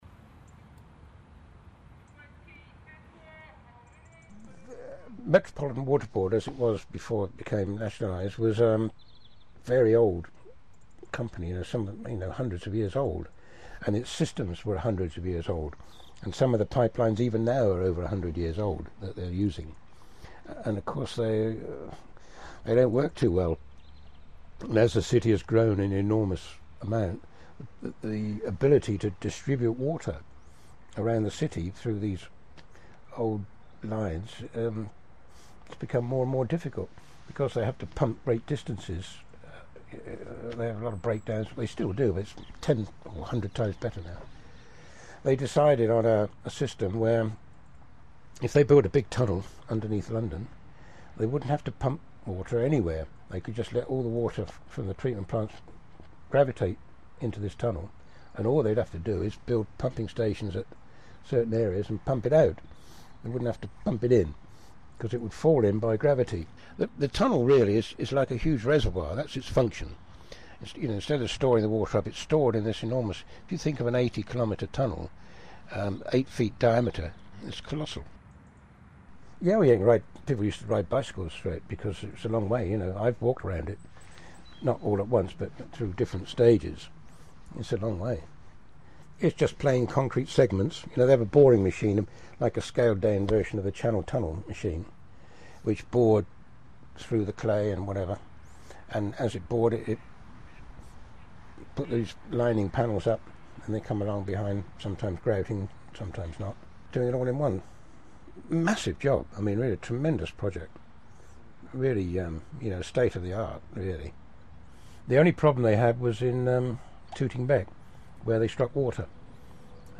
Memoryscape logo Voices from the hidden history of the Thames
Today the river supplies most Londoners with drinking water via the enormous Thames ring main which runs 40 metres underground near here. I asked an engineer who worked on the project to explain the new system.